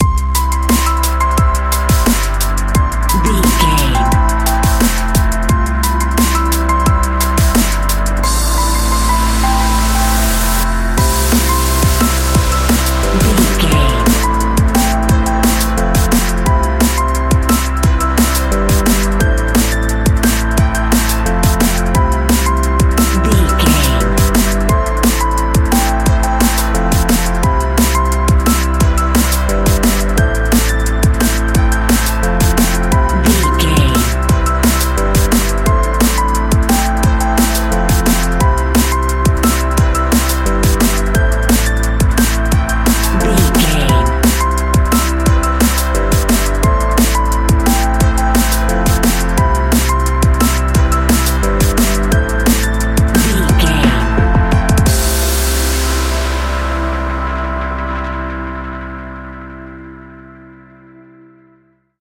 Ionian/Major
Fast
driving
uplifting
futuristic
hypnotic
drum machine
synthesiser
electric piano
sub bass
synth leads